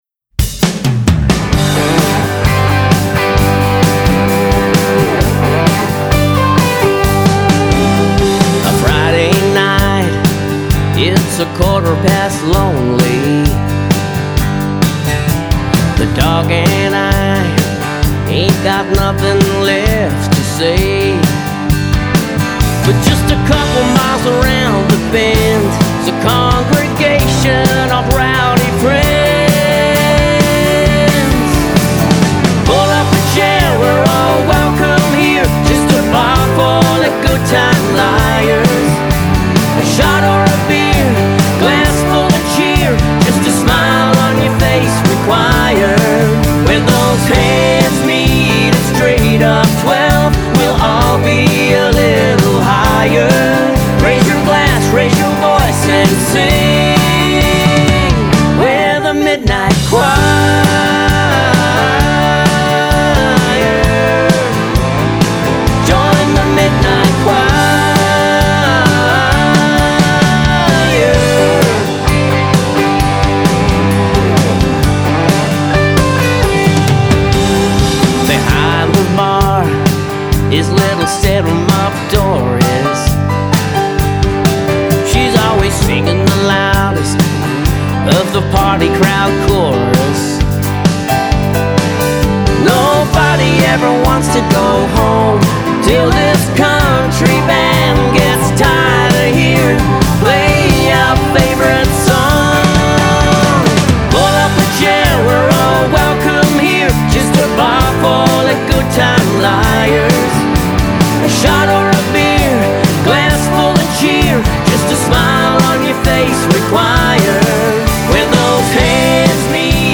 country duo
is a joyous, high-energy song